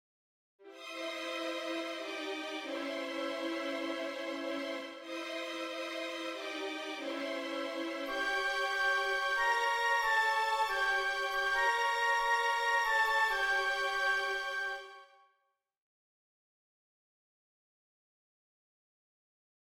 En un rugissement, les violons 1 et 2 prennent le thème B. Ils sont divisés en deux fois trois parties, les violons 2 se situant une octave au dessous des violons 1.
Le langage est ici extrêmement dissonant et il va mêler en un tout démoniaque un grand nombre de plans sonores dont voici les exemples.
Voici maintenant le thème B, alors qu’il est joué pour la première fois par les violons, puis repris par les bois (flûtes et hautbois) au chiffre 36 :
theme-b-dissonant-ch-37-1ere.mp3